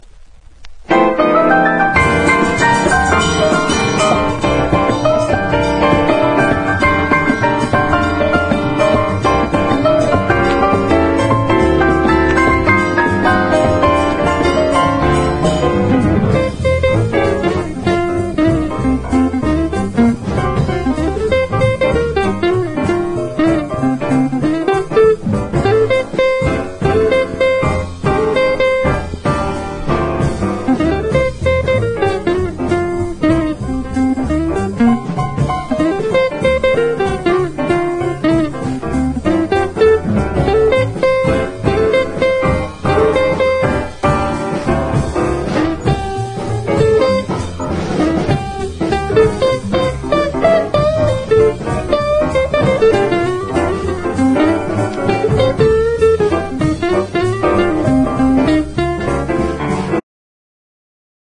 JAZZ / DANCEFLOOR / RHYTHM & BLUES / POPCORN
深夜のクラブに映えるバーレスク・ダンス/DJユースな人気曲をコンパイル！